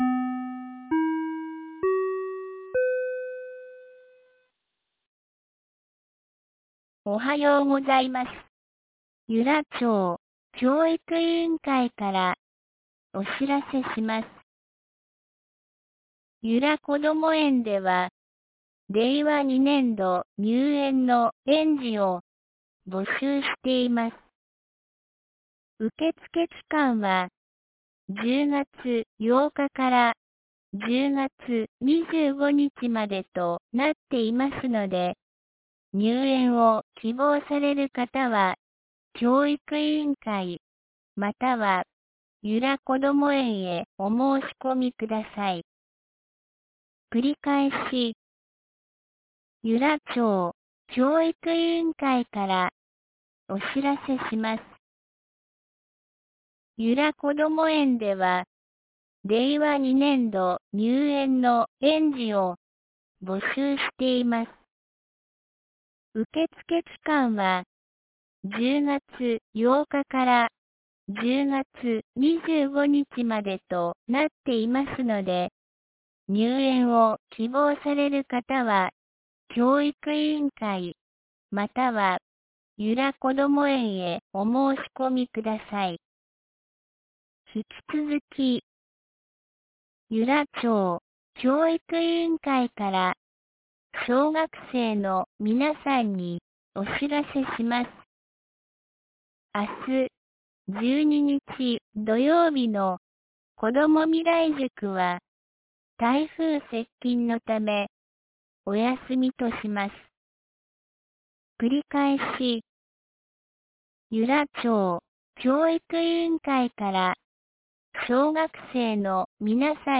2019年10月11日 07時52分に、由良町から全地区へ放送がありました。
放送音声